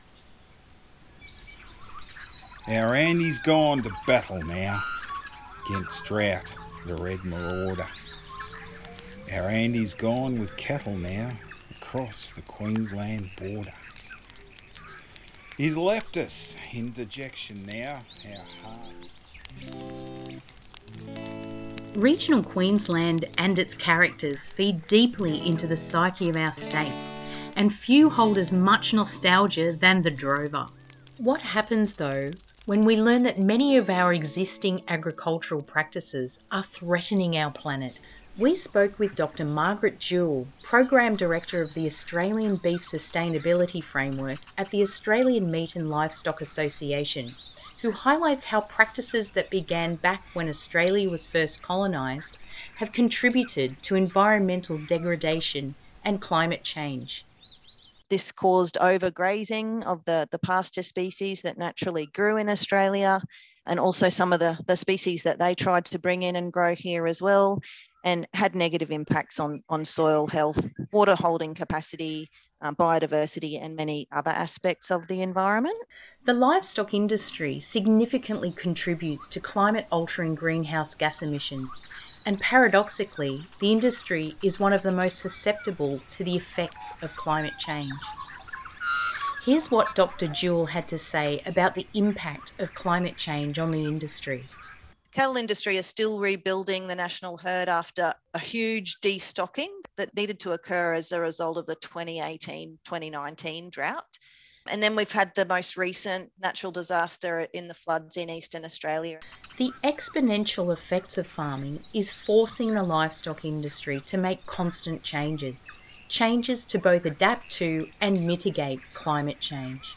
With the topics of environmental degradation and food security at the forefront of our approach, and through the lenses of the agricultural livestock industry in Queensland and the emerging new ideas surrounding cellular technology, our podcast Symbolism, Sustenance and Science, aims to highlight the complexities of meaningfully communicating environmental topics to a broad audience. Narration and quotes from expert interviews and a webinar are delivered amidst the sounds of nature and the verses of a renowned historical poem that links both tradition and innovation.